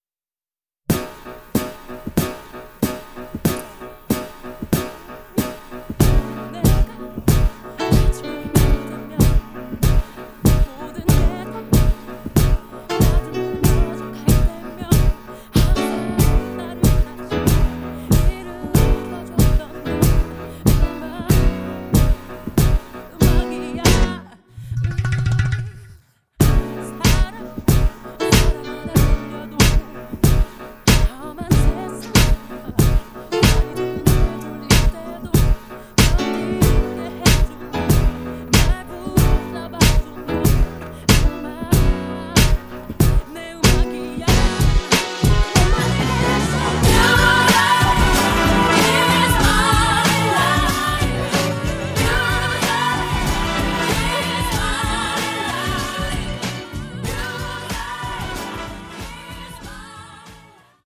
음정 원키 (코러스
장르 가요 구분